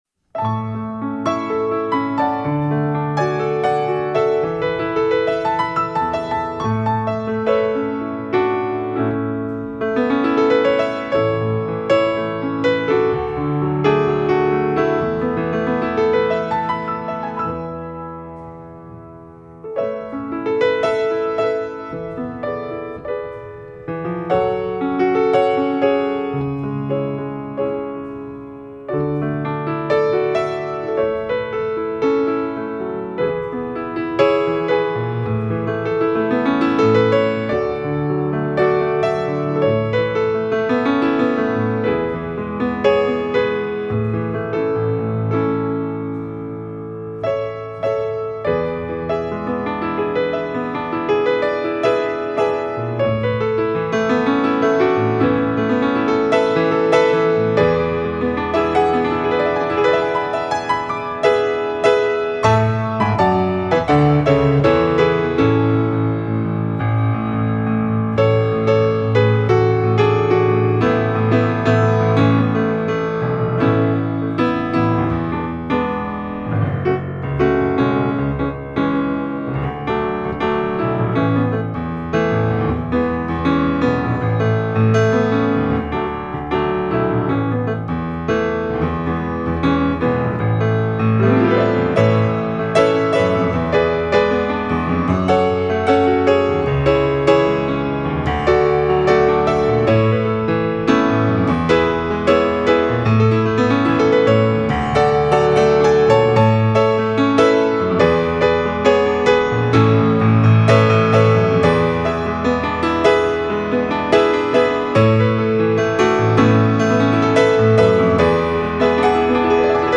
Konzertpianistin aus Minsk,Weißrussland
Koljada - russisches Folklorestück